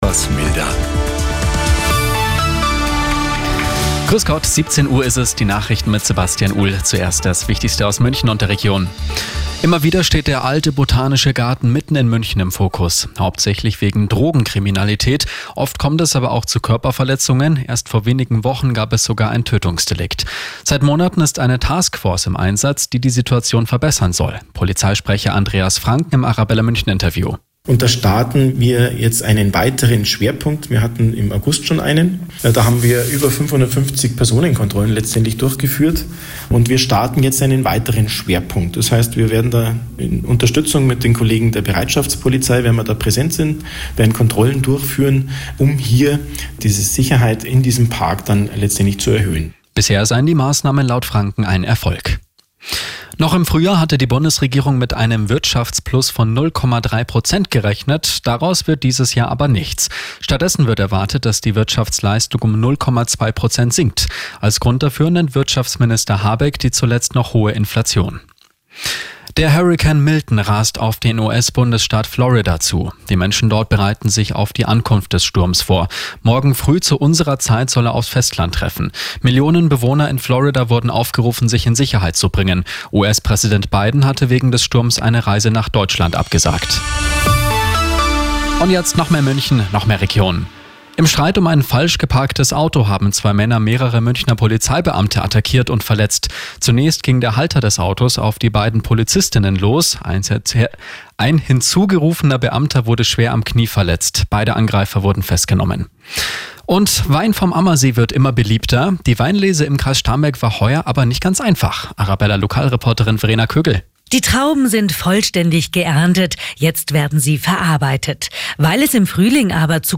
Die Radio Arabella Nachrichten von 19 Uhr - 09.10.2024